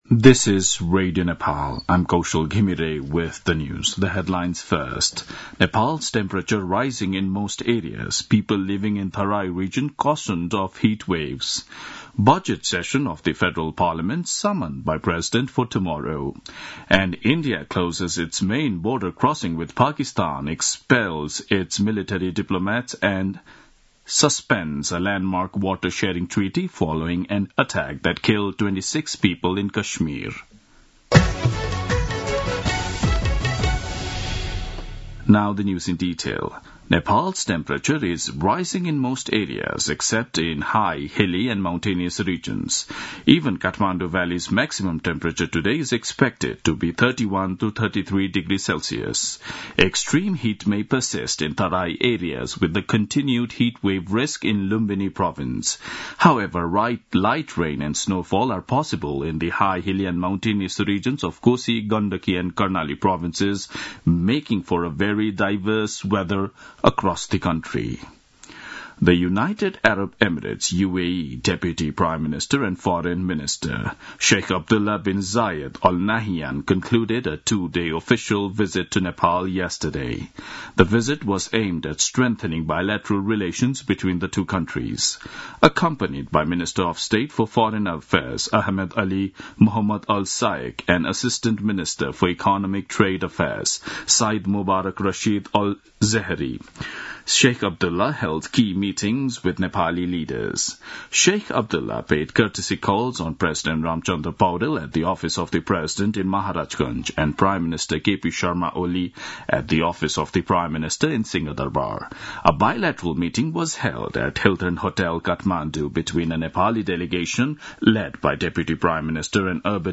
दिउँसो २ बजेको अङ्ग्रेजी समाचार : ११ वैशाख , २०८२